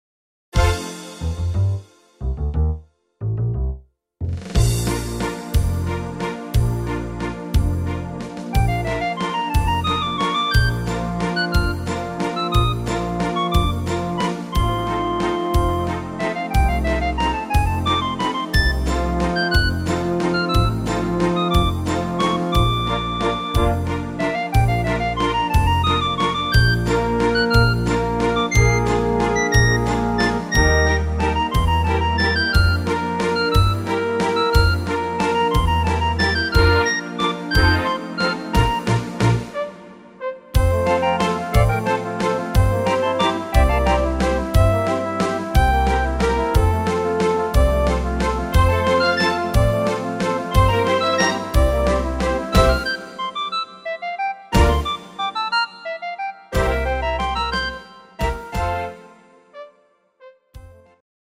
instr. Strings